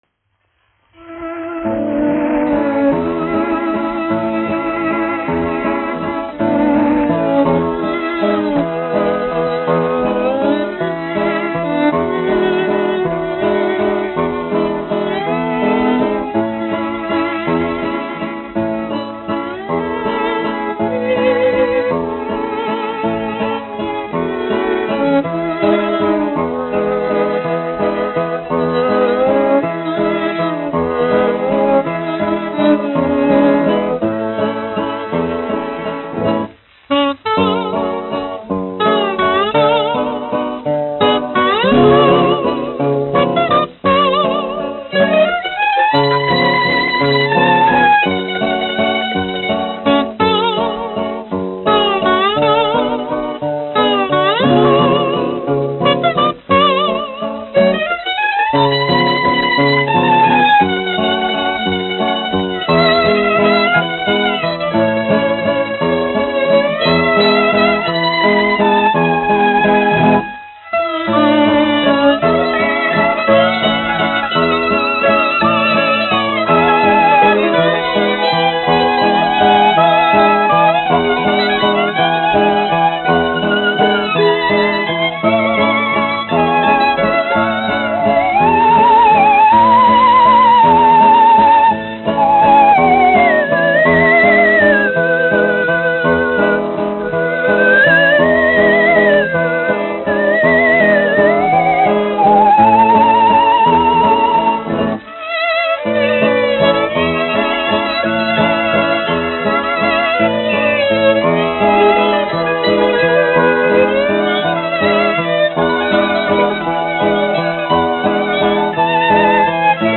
1 skpl. : analogs, 78 apgr/min, mono ; 25 cm
Valši
Populārā instrumentālā mūzika
Skaņuplate
Latvijas vēsturiskie šellaka skaņuplašu ieraksti (Kolekcija)